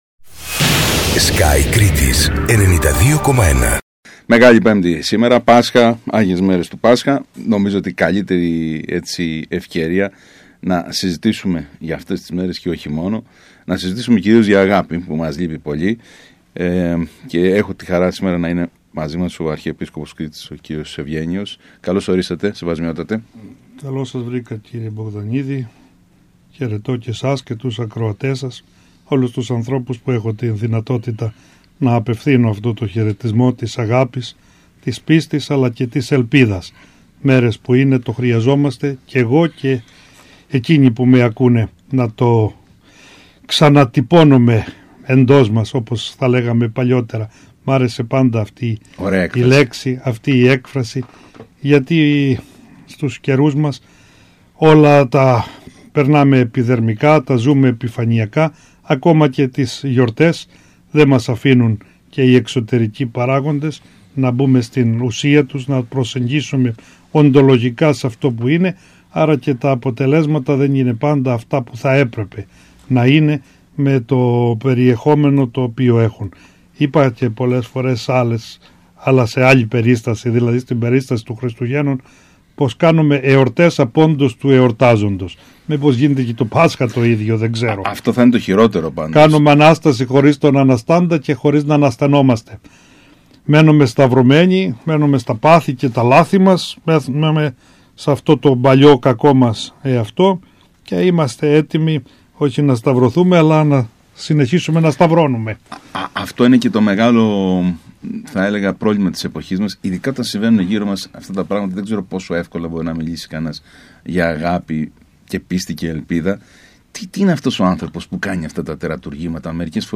Δεν ήταν μια απλή ραδιοφωνική συζήτηση με τον Αρχιεπίσκοπο Κρήτης κ. Ευγένιο με αφορμή τις άγιες Μέρες του Πάσχα.